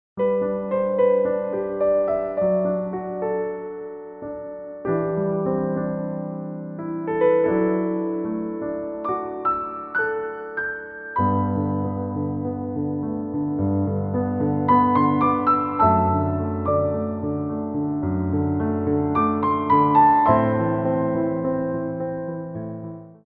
Ports de Bras / Revérance
4/4 (8x8)